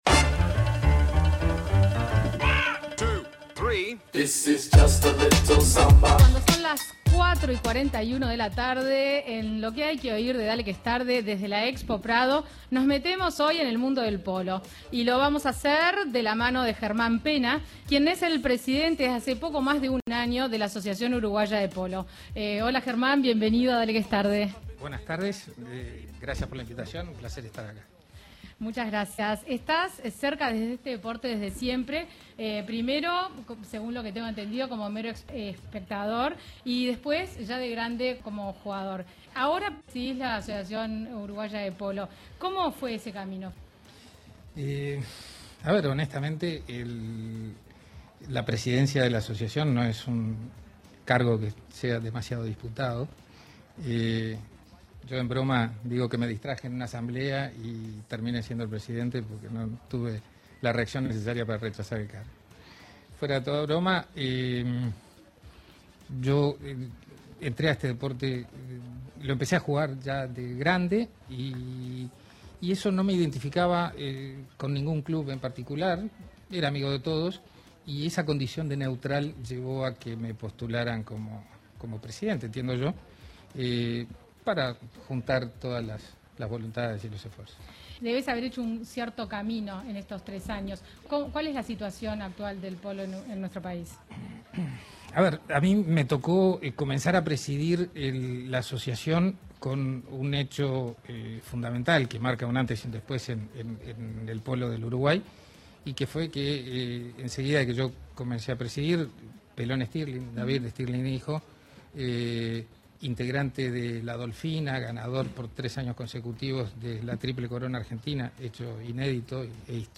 A continuación los invitamos escuchar la entrevista en Dale Que Es Tarde .